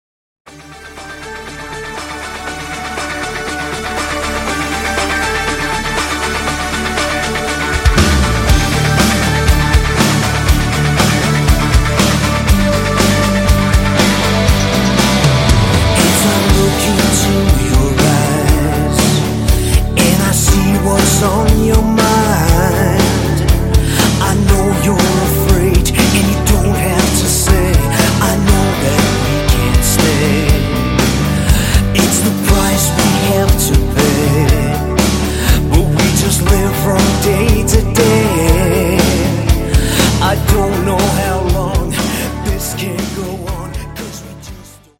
Category: Power AOR / melodic hard rock